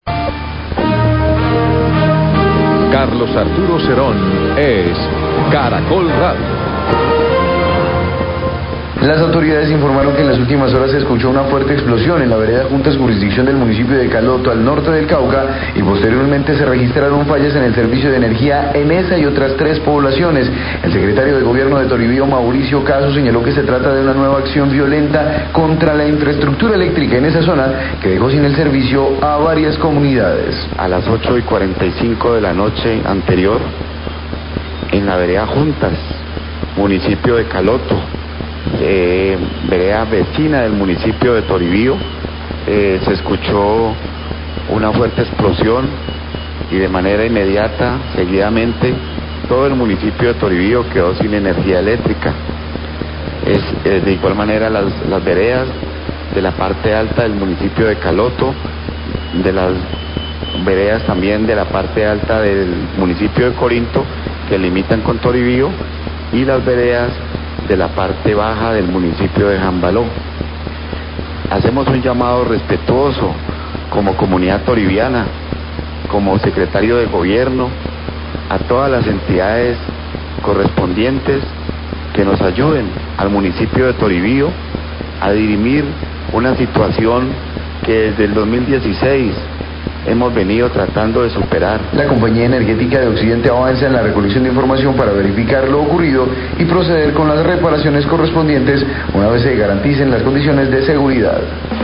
Radio
Luego de fuerte explosión en la vereda Juntas, Caloto se registraron fallas en el servicio de energía, se trata de un nuevo atentado terrorista contra la infraestructura eléctrica que dejó sin servicio a varias comunidades. Declaraciones del Secretario de Gobierno de Toribío, Mauricio Caso.